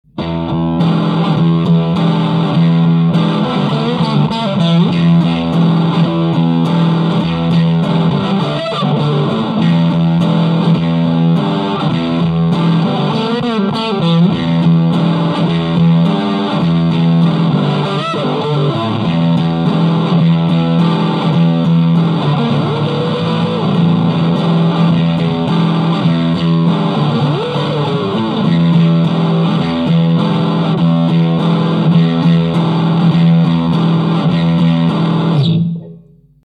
JH FUZZ ON (569kbMP3)
JH FUZZのつまみは両方とも１２時です。
Guitar Fender STRTOCASTER
Amplifier VOX AD30VT UK70'GAIN10
VOLUME10,TREBLE10,MIDDDLE10,BASS6